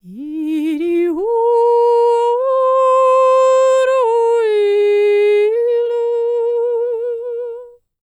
K CELTIC 29.wav